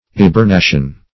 Eburnation \E`bur*na"tion\, n. [L. eburnus of ivory, fr. ebur